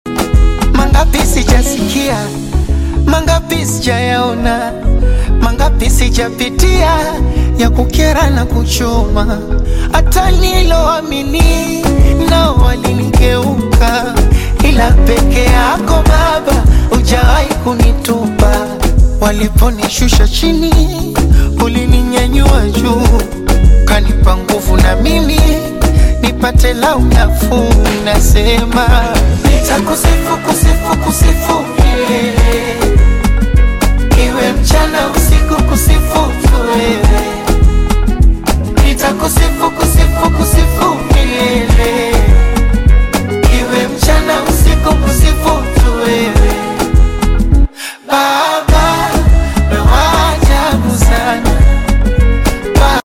heartfelt Afro-Pop/Bongo Flava single
Genre: Bongo Flava